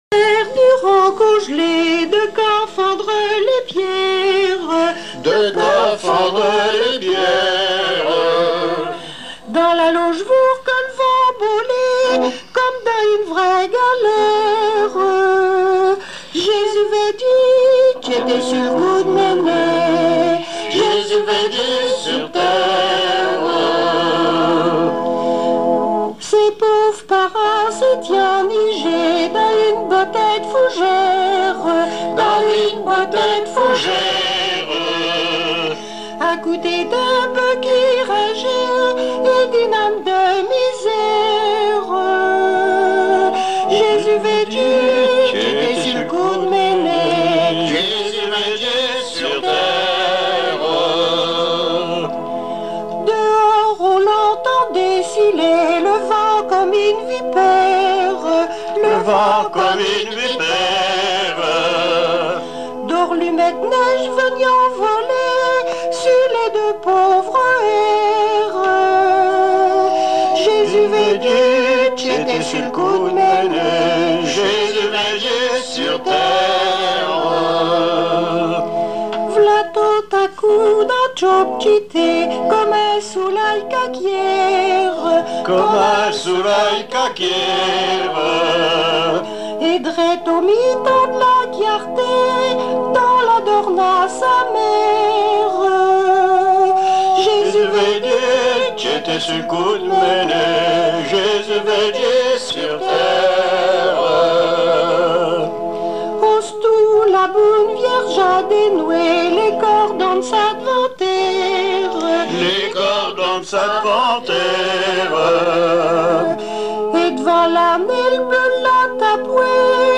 Noël
Patois local
émission La fin de la Rabinaïe sur Alouette
Pièce musicale inédite